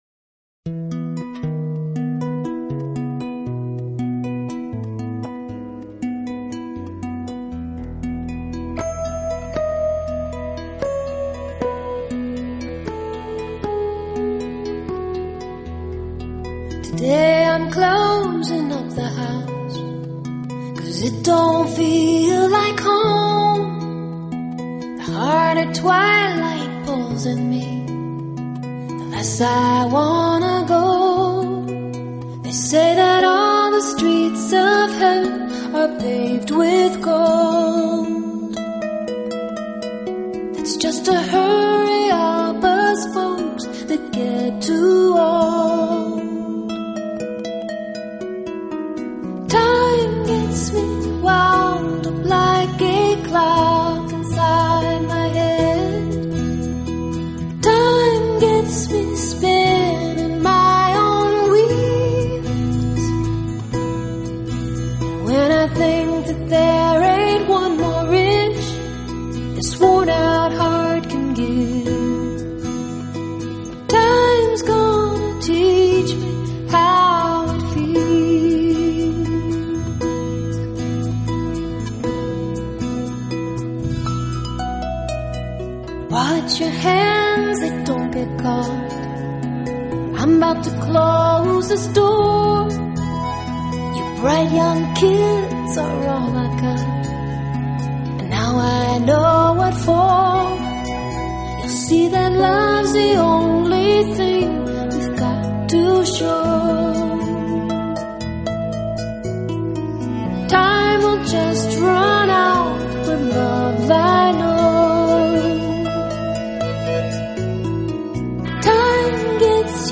音清晰纯净、情感丰富，正如她演奏的竖琴音色一样，充满了光辉润泽的质感。
她创作的曲风虽然被归属于民谣类，但却掺进了许多别的音乐元素而显得多样化。
配上她清丽高亢的歌声，非常悦耳动听。